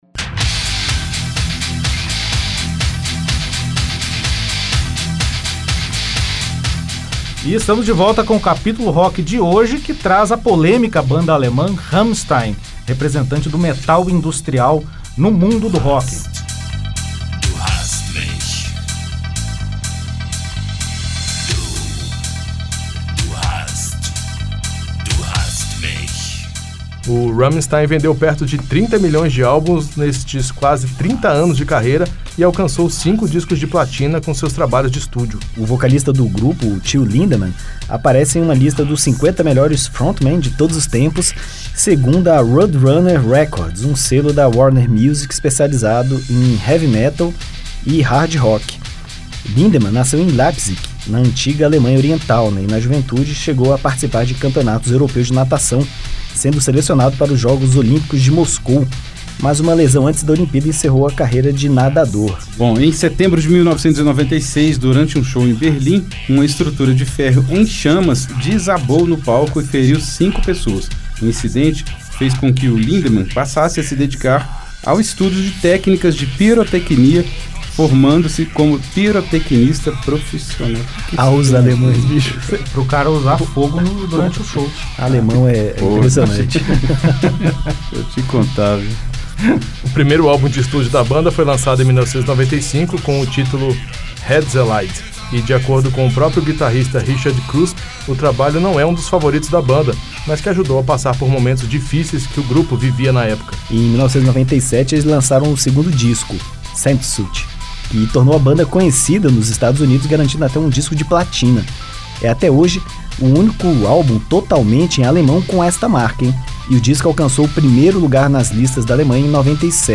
O Capítulo Rock traz aos ouvintes a história da banda alemã Rammstein. Grupo formado nos anos 90 e que traz no seu repertório o rock industrial alemão.
Além do som que mistura heavy metal com elementos de música eletrônica, o Rammstein se caracteriza por shows com performances teatrais e muita pirotecnia.